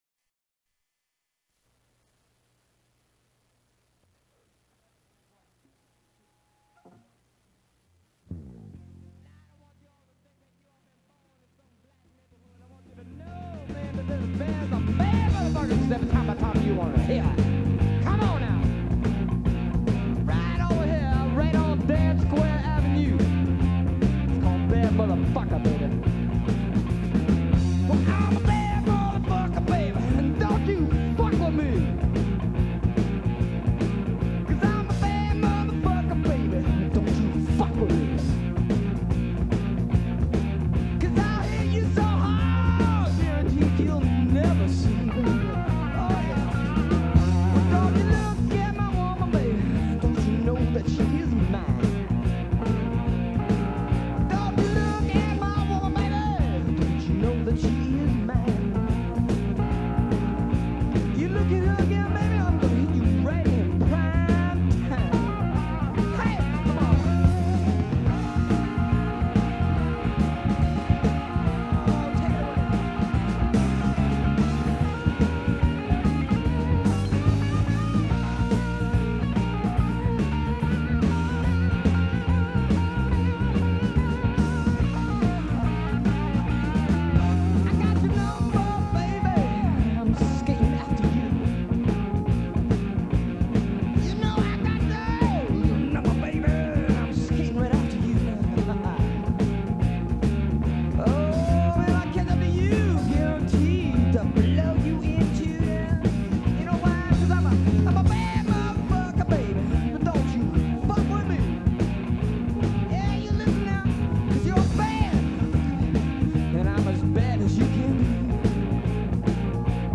A Blues Rock Dance Groove with a Definite Attitude, I’d Say…